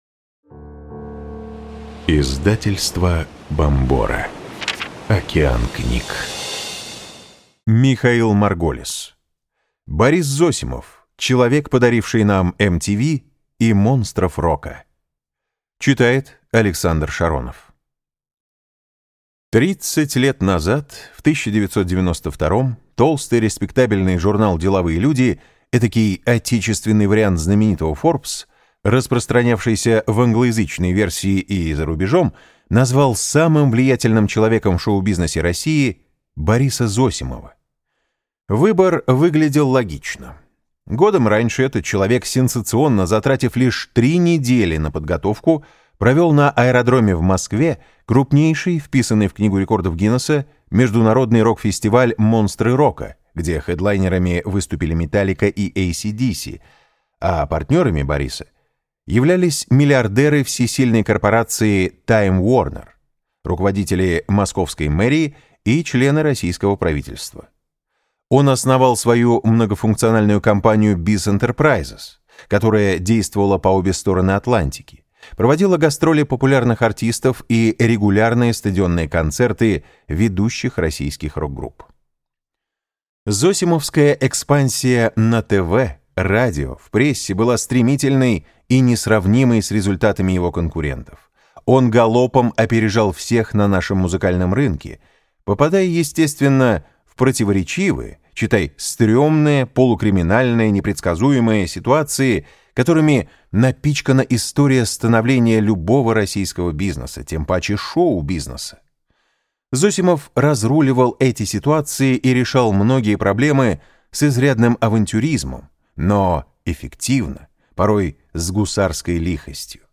Аудиокнига Борис Зосимов. Человек, подаривший нам MTV и «Монстров рока» | Библиотека аудиокниг